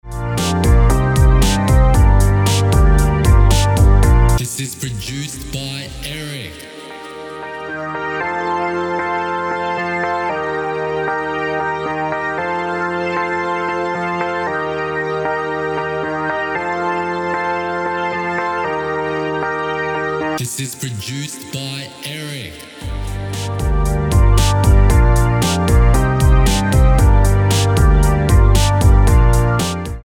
Key: G minor Tempo: 115BPM Time: 4/4 Length: 3:20